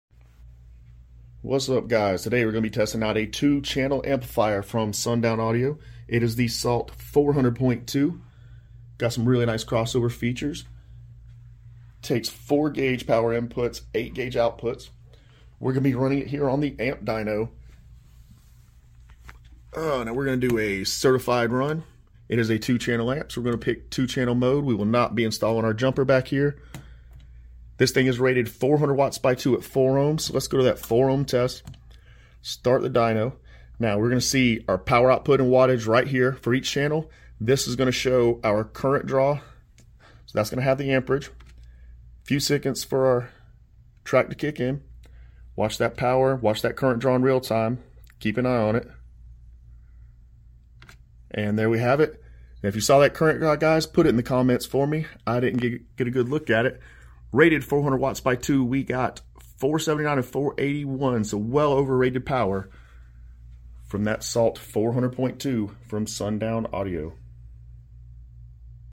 Sundown Audio Salt-400.2 certified 4ohm amp dyno test